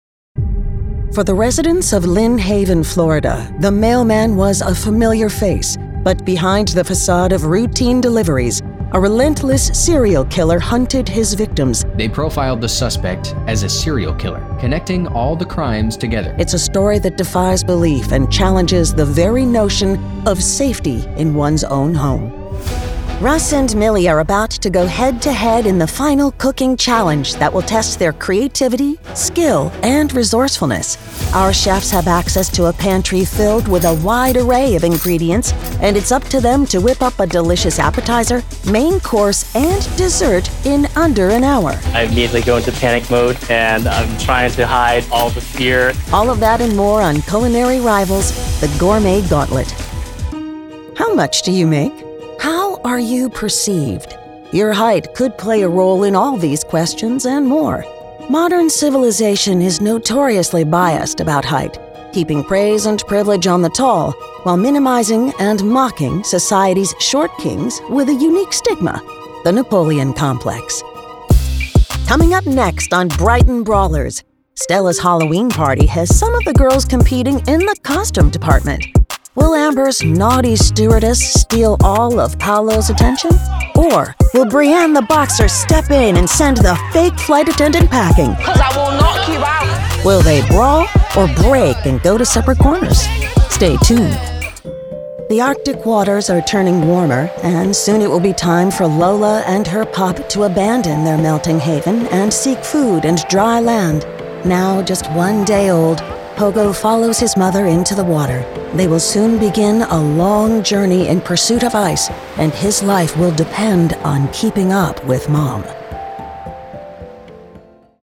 Voice Actor
Warm and engaging, voicing the Mom Next Door, your favorite Granny, or the Creature Under the Stairs.
Studio specs: Double-walled treated ISO booth, Sennheiser MK 4 mic, Mogami Gold cable, Yamaha preamp, Reaper, Izotope 7, external mac mini.